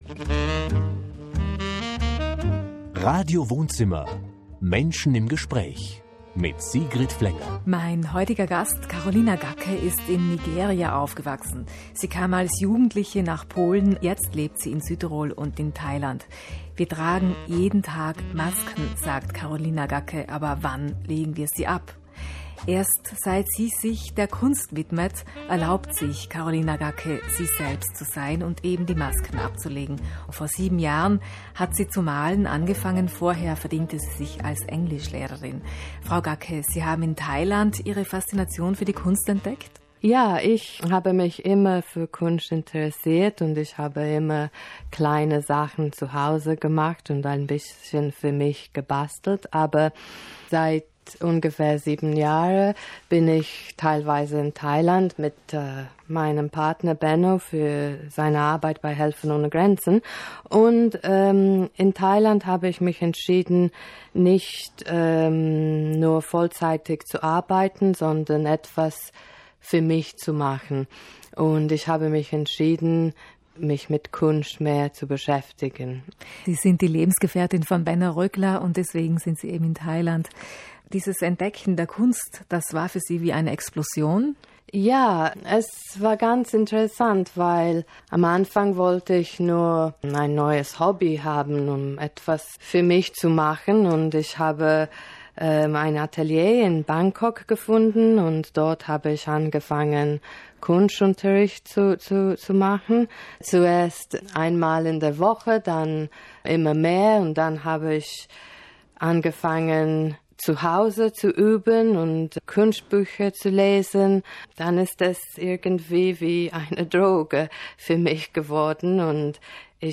I recently spoke about my art, my life and my current exhibition, on two local South Tyrolean radio stations, Radio Grüne Welle and RAI Sender Bozen.